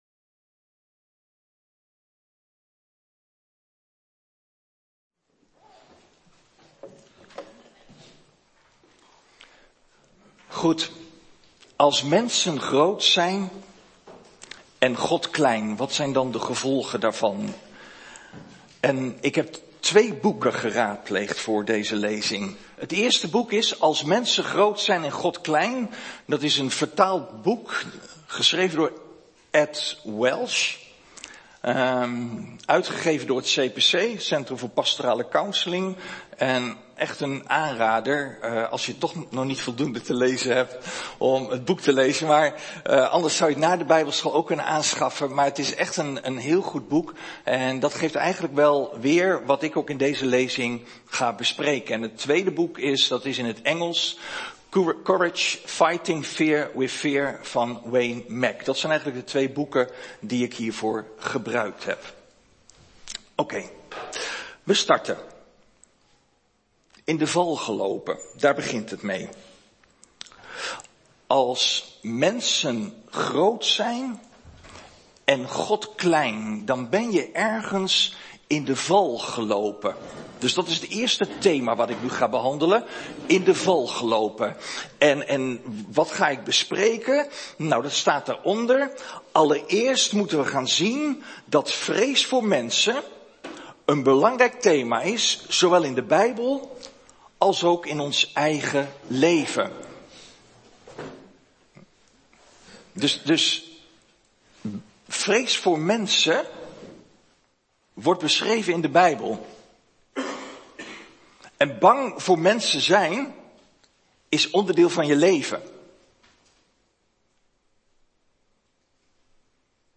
Een preek over 'Vrees voor God en vrees voor mensen (deel 1)'.